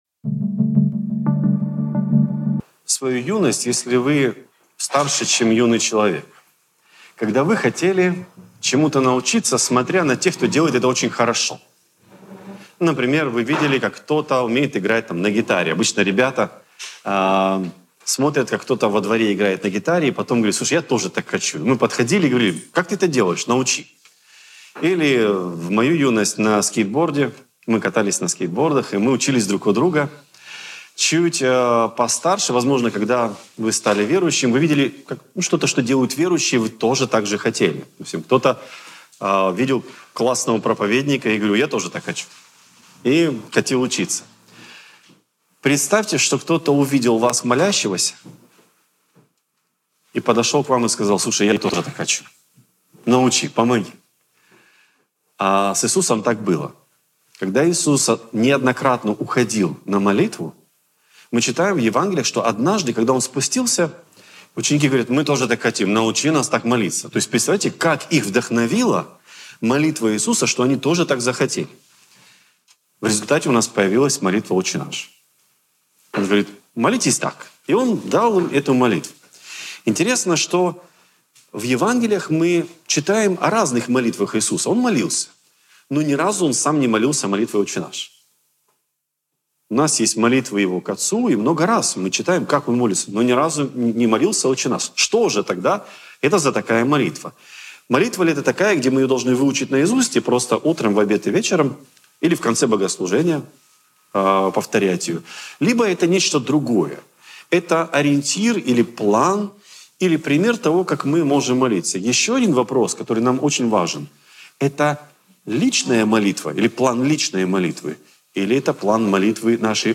«Преображение» | Церковь евангельских христиан-баптистов